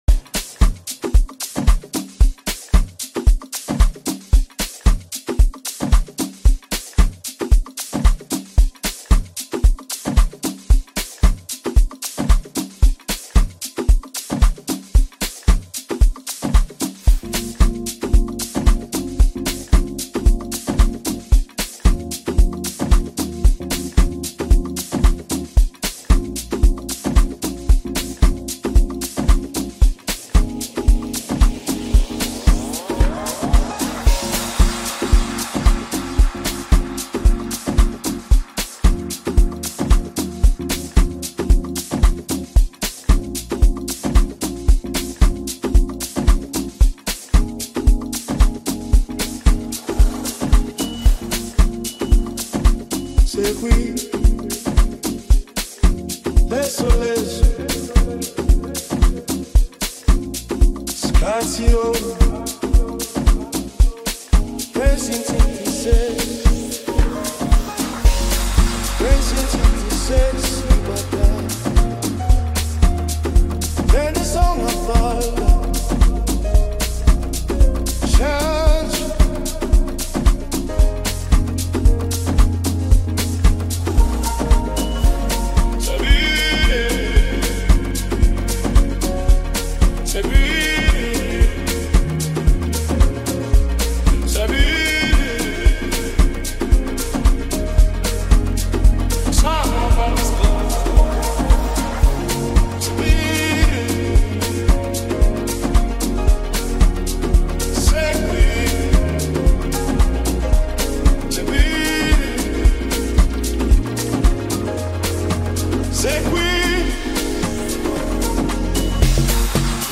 Home » Amapiano » Hip Hop » Latest Mix
Talented vocalist